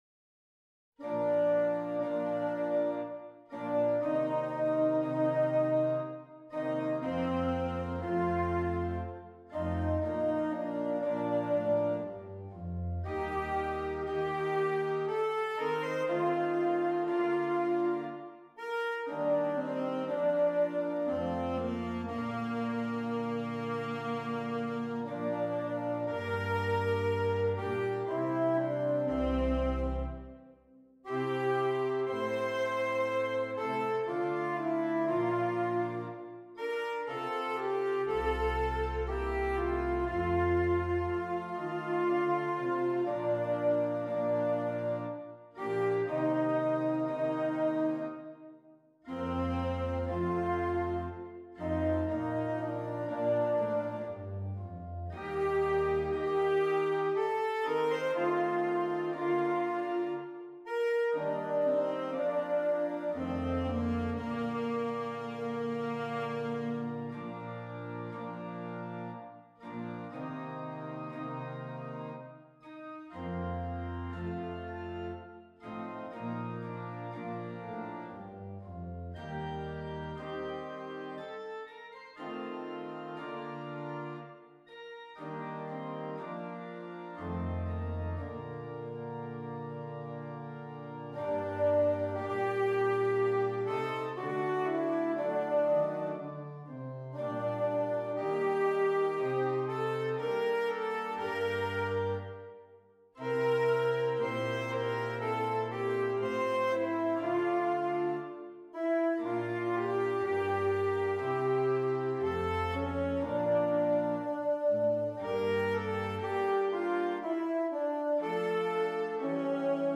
Alto Saxophone and Keyboard